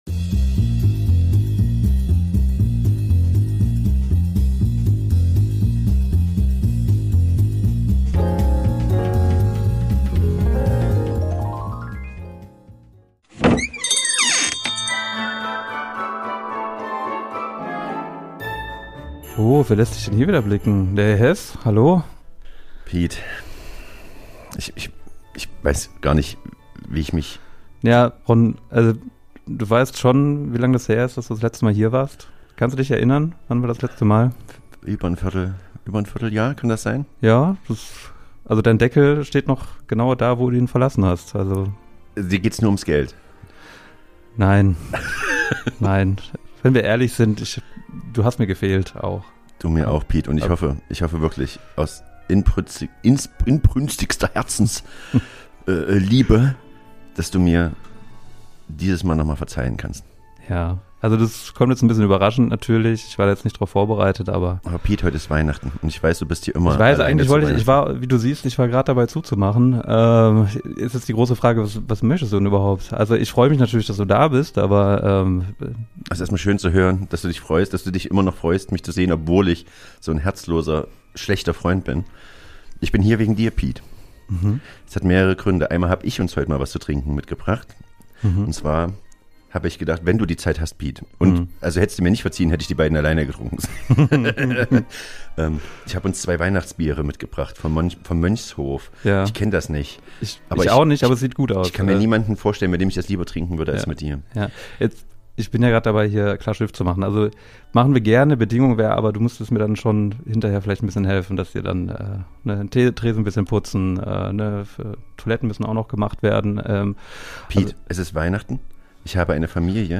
Die Soundeffekte kommen von Pixabay.